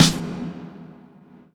Rev_snr.wav